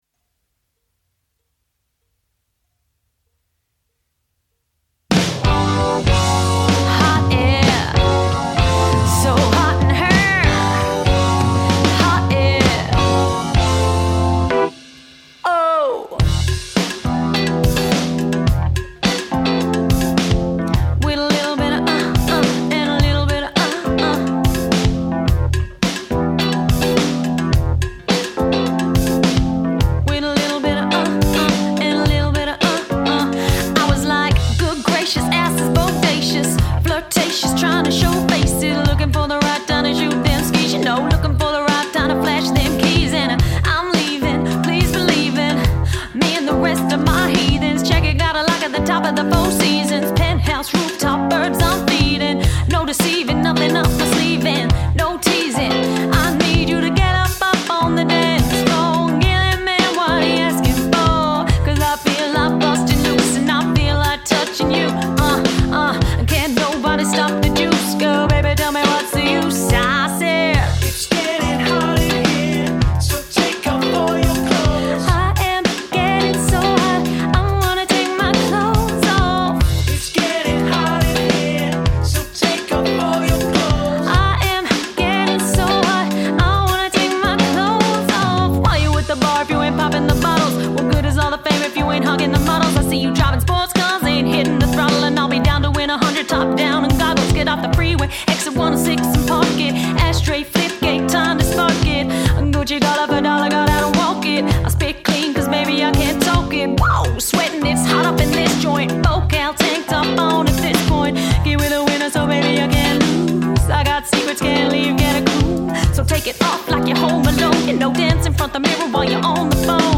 party band wedding band